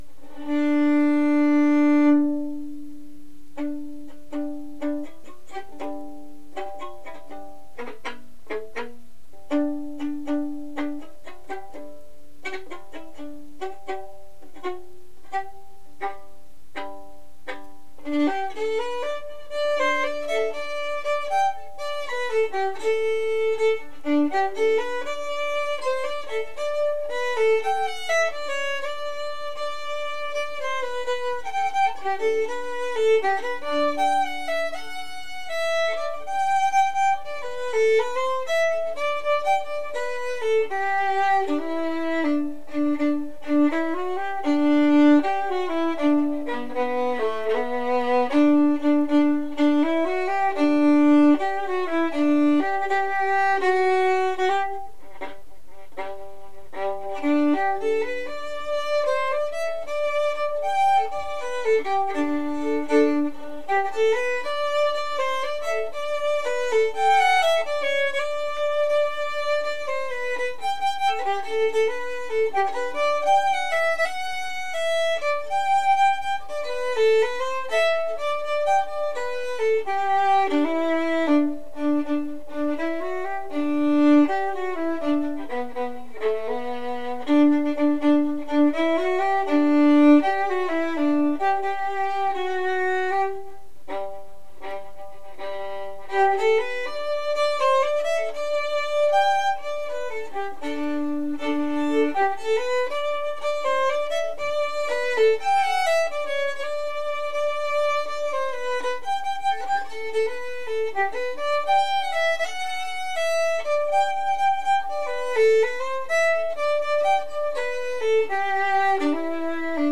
Each link connects to a Morris Dance tune, to play for practice ...
. . . Cotswold Dances: